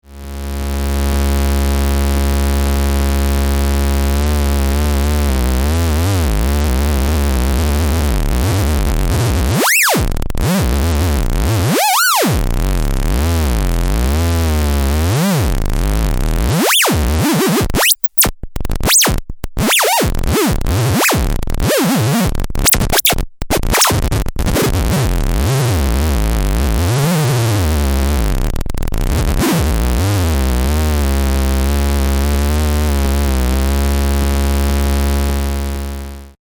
CVランダム・アウトをA-111のCVへ接続 (波形はA-111矩形波を使用)